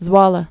(zwôlə, zvôlə)